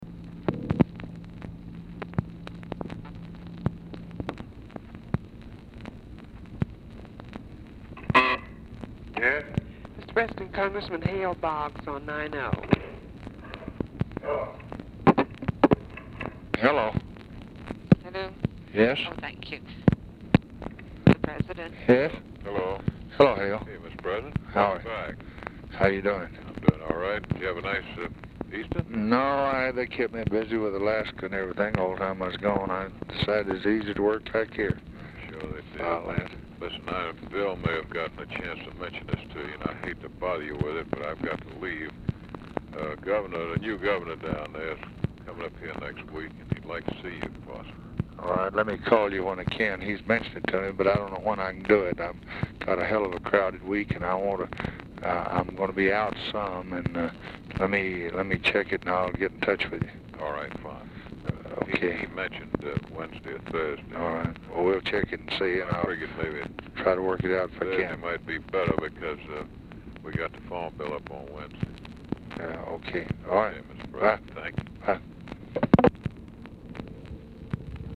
Telephone conversation # 2820, sound recording, LBJ and HALE BOGGS, 4/1/1964, 5:22PM | Discover LBJ
Format Dictation belt
Location Of Speaker 1 Oval Office or unknown location
Specific Item Type Telephone conversation Subject Congressional Relations National Politics